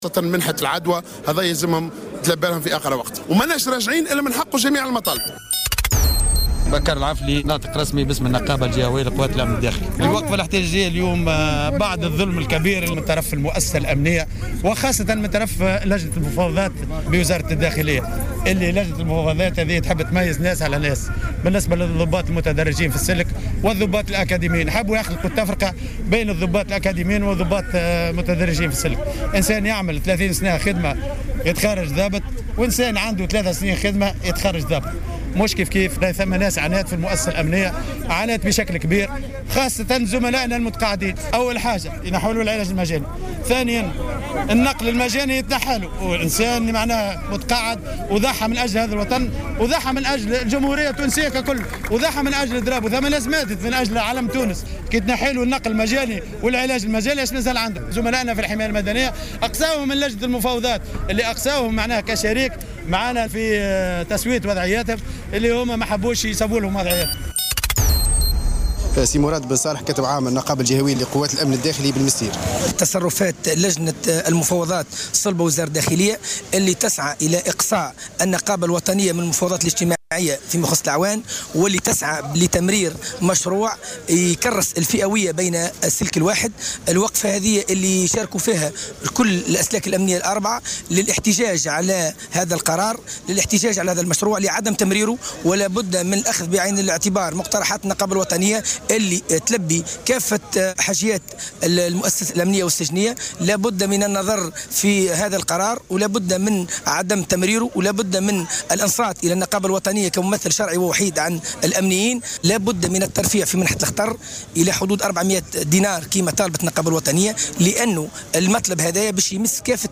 روبورتاج